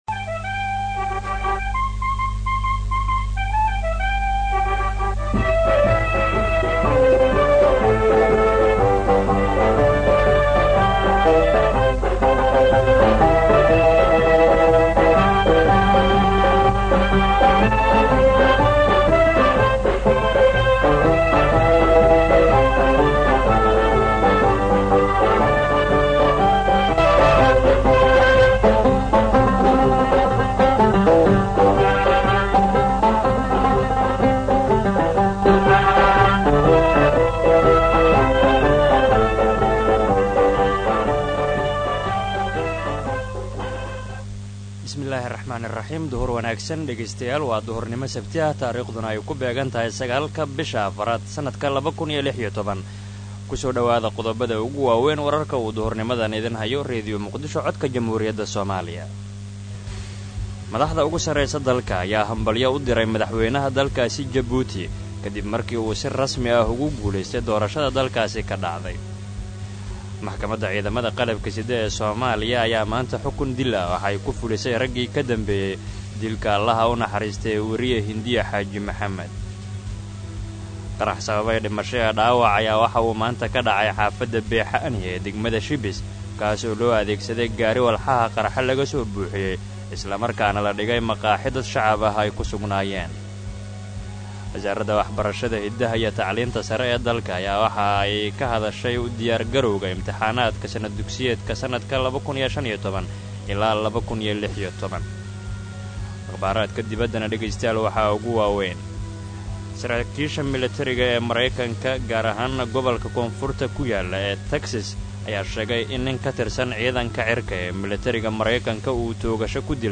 Dhageyso Warka Duhur ee Radio Muqdisho